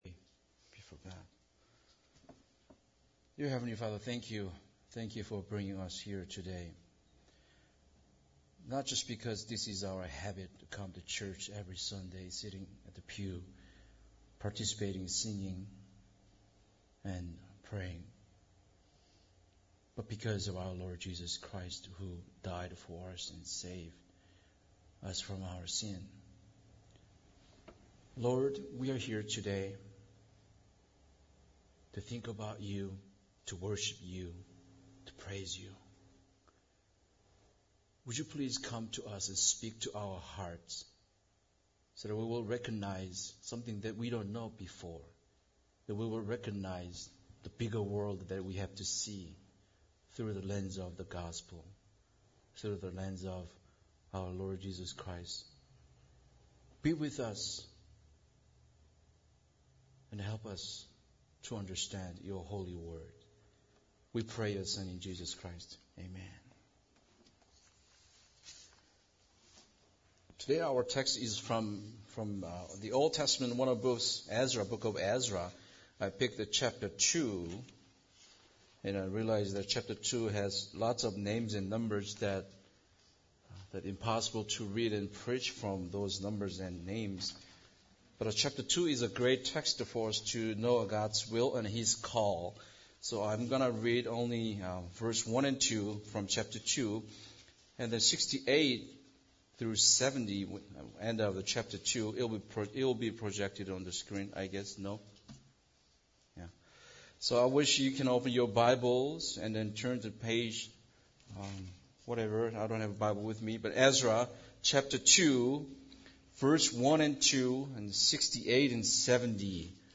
Ezra 2:58-70 Service Type: Sunday Service Bible Text